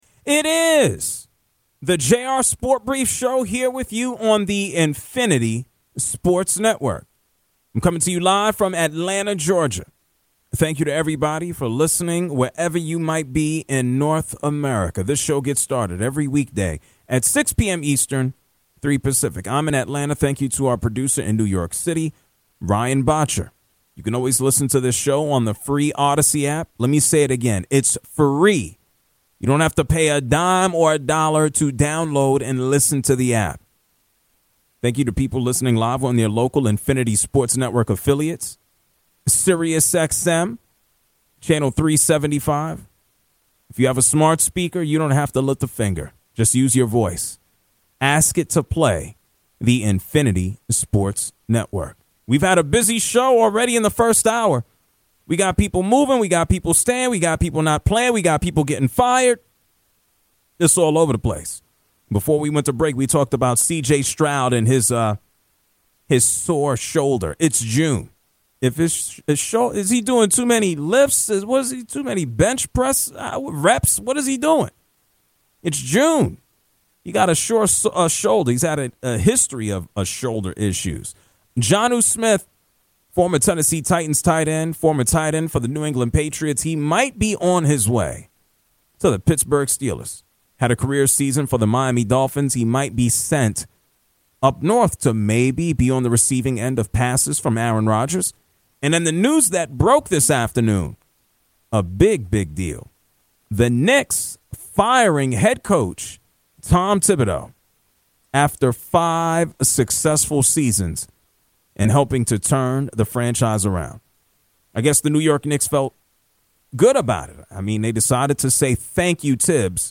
Calls on the Tom Thibodeau firing. |